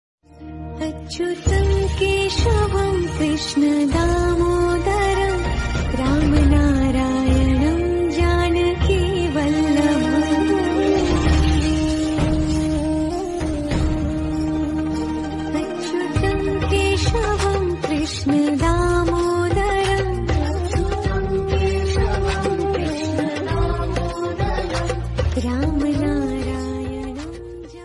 Devotional Rock Band